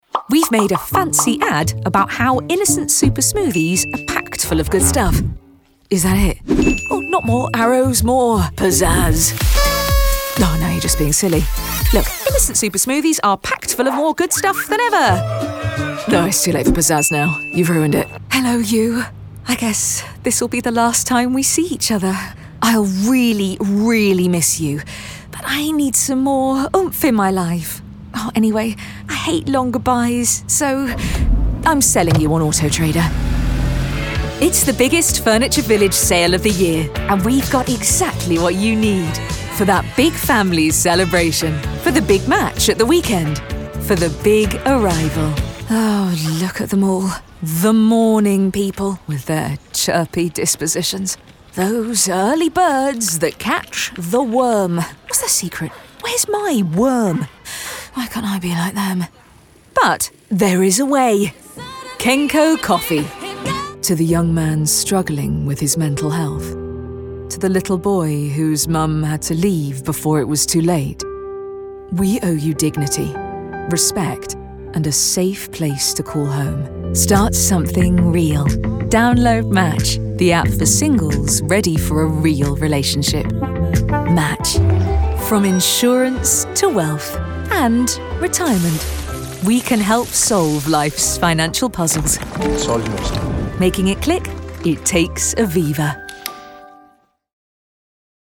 Inglês (britânico)
Demonstração Comercial
Estúdio construído profissionalmente.
Mezzo-soprano